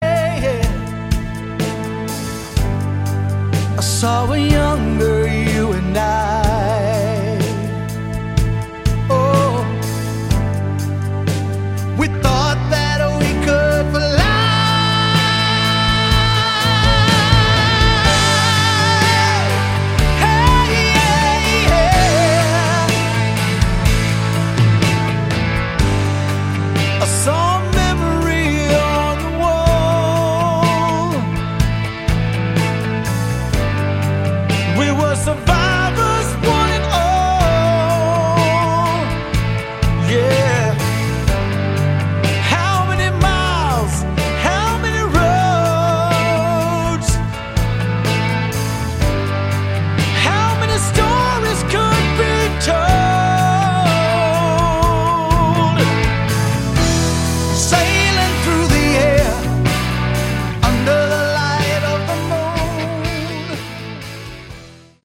Category: Hard Rock
guitar, bass, keybaords, vocals
drums, percussion
Melodic Hard Rock with an AORish feel here & there